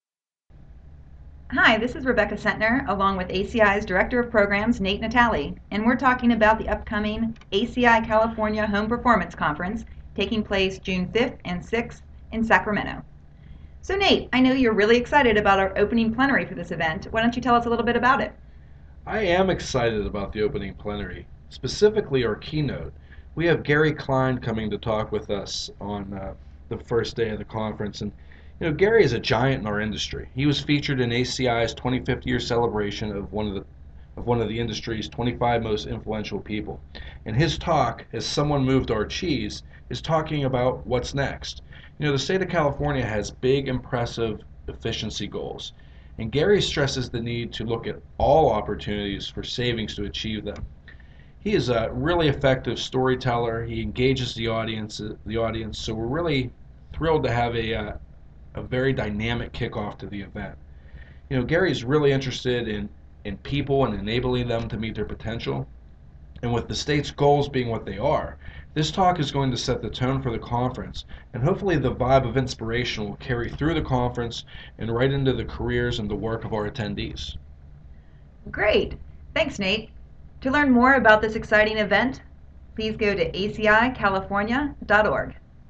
Opening plenary and keynote